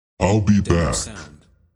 “I’ll be back” Clamor Sound Effect
Can also be used as a car sound and works as a Tesla LockChime sound for the Boombox.